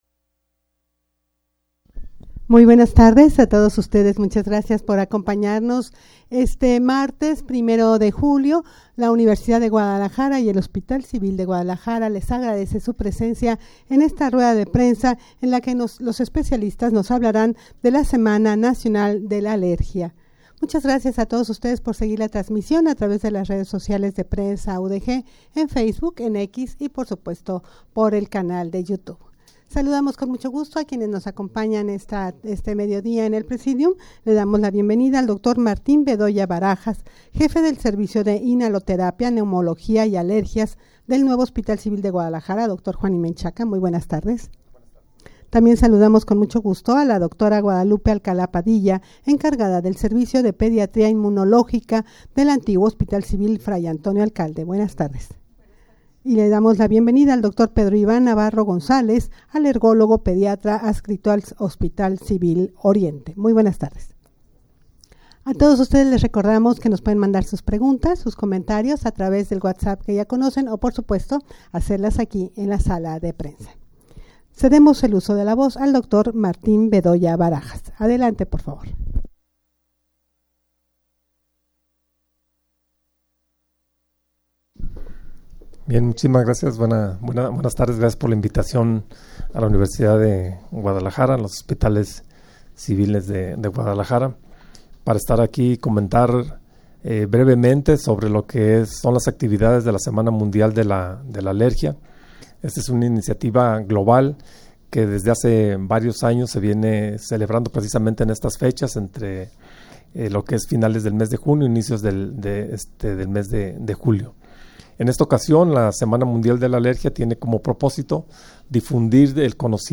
rueda-de-prensa-para-hablar-de-la-semana-nacional-de-la-alergia.mp3